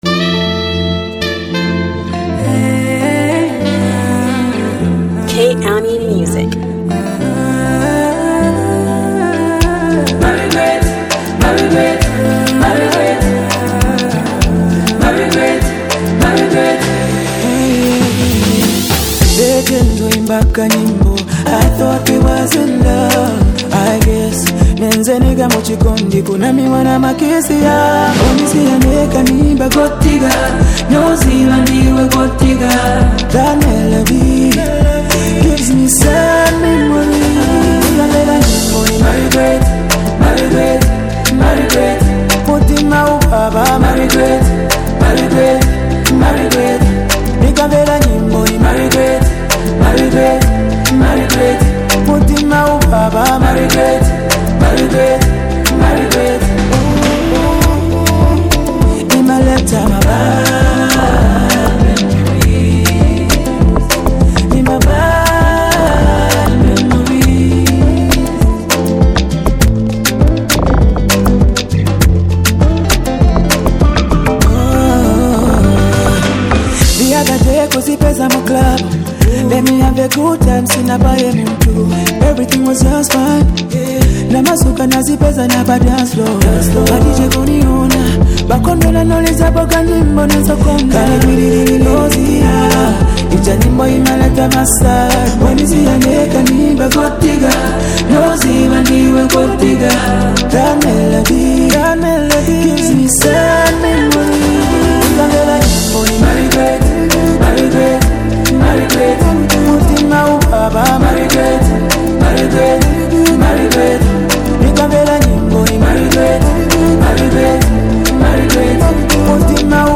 Zambian song that blends elements of hip-hop and Afrobeat.